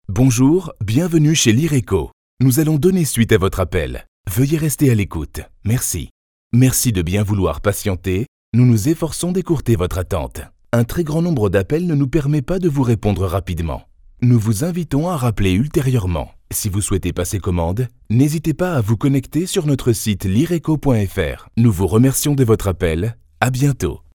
Commercieel, Natuurlijk, Veelzijdig, Vriendelijk, Zakelijk
Telefonie